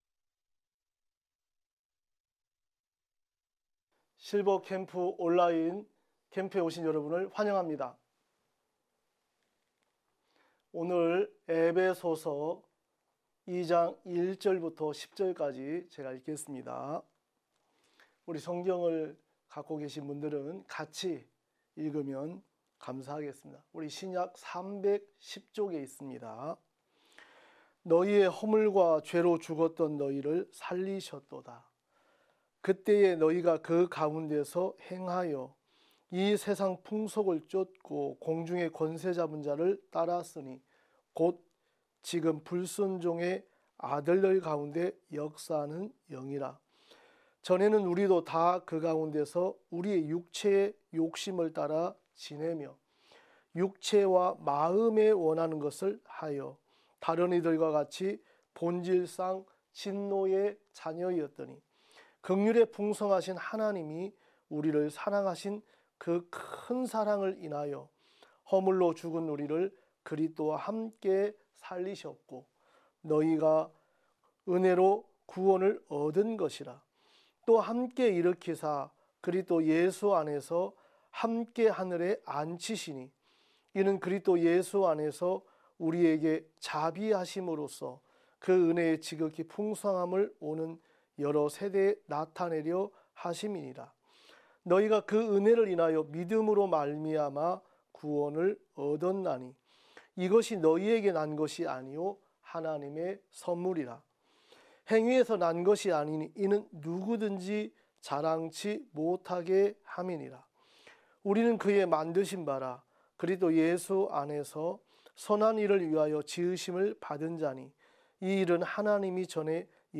매해 여름과 겨울, 일 년에 두 차례씩 열리는 기쁜소식선교회 캠프는 아직 죄 속에서 고통 받는 사람들에게는 구원의 말씀을, 일상에 지치고 마음이 무뎌진 형제자매들에게는 기쁨과 평안을 전하고 있습니다. 매년 굿뉴스티비를 통해 생중계 됐던 기쁜소식 선교회 캠프의 설교 말씀을 들어보세요.